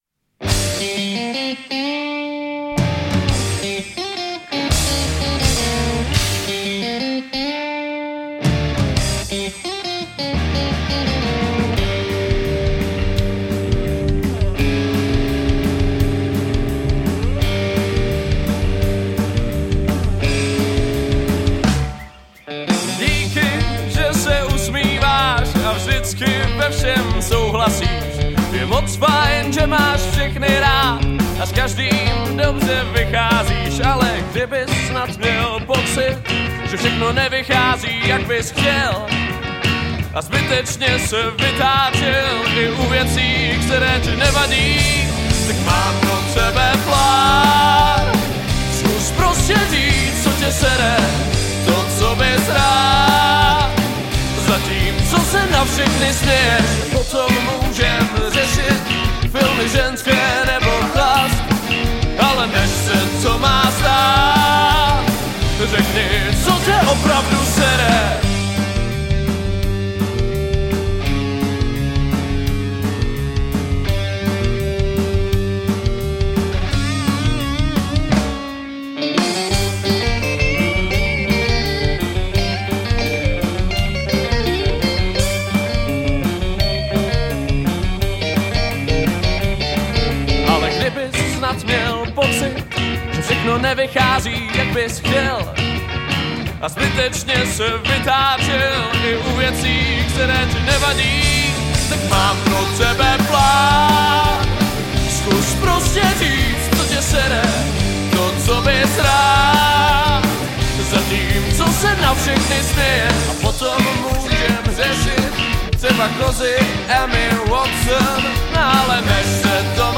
Žánr: Rock
Heavy rockabilly.